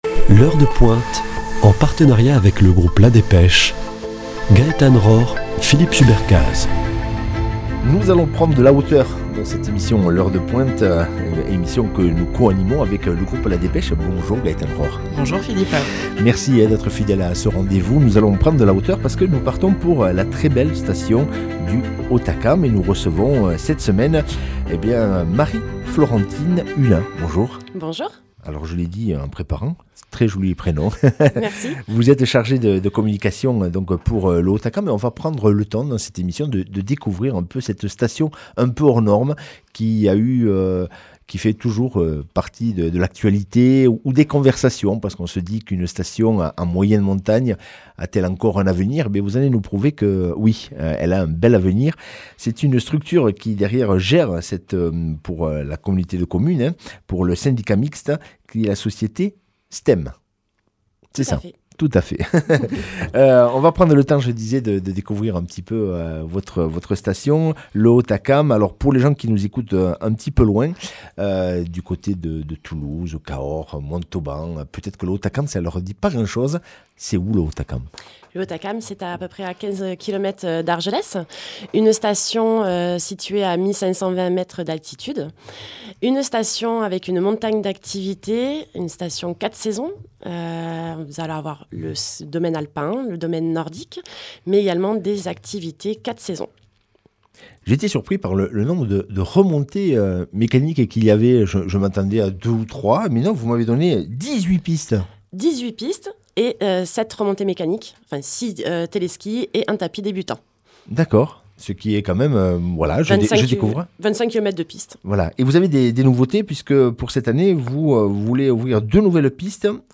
Une interview réalisée dans le cadre du partenariat de La Dépêche du Midi avec Radio Présence.